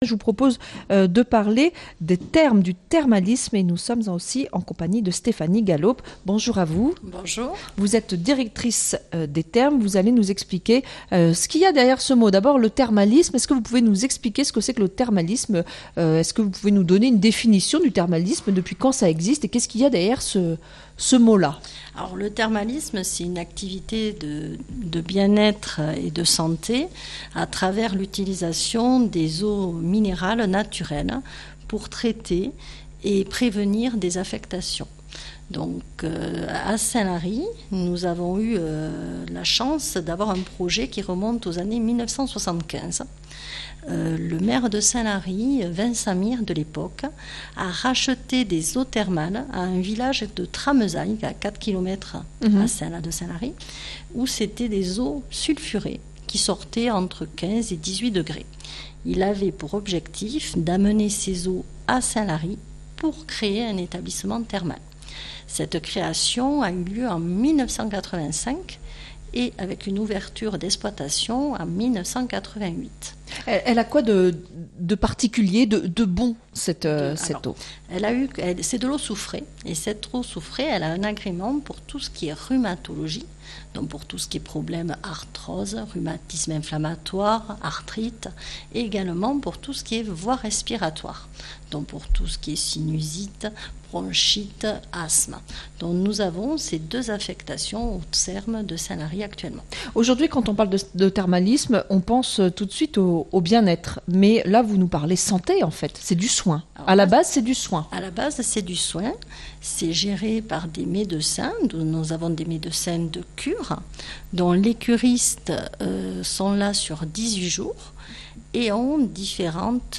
Direct St Lary Partie C